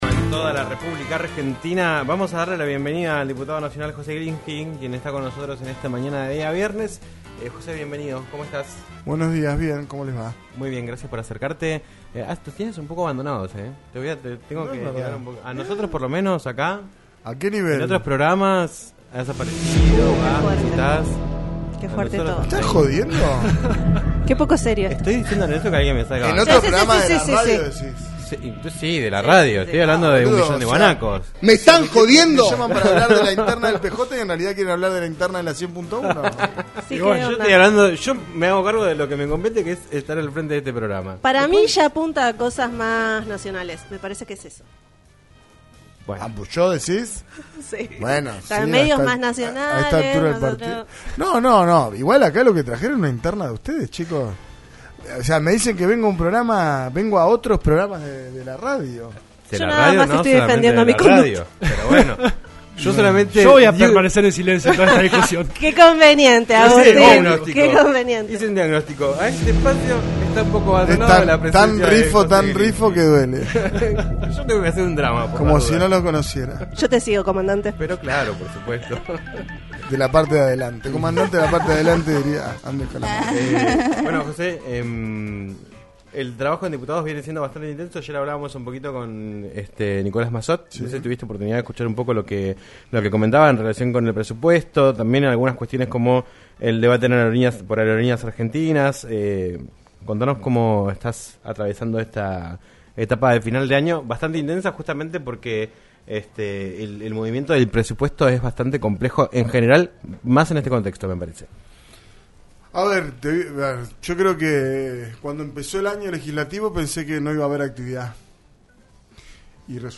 José Glinski, diputado nacional por Chubut del bloque Unión por la Patria, pasó por los estudios de LaCienPuntoUno para hablar en "Un Millón de Guanacos" sobre su trabajo en el Congreso de la Nación en estos primeros meses, la gestión de Ignacio Torres como gobernador de Chubut y la interna peronista a nivel nacional que no pudo ser.